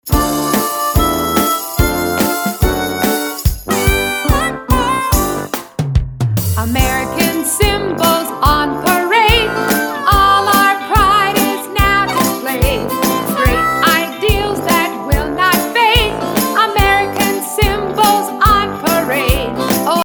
Downloadable Musical Play with Album Sheet Music.